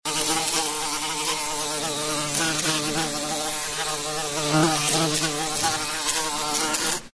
Жужжание одинокой пчелы